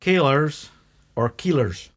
[KAYL-ers or KEEL-ers]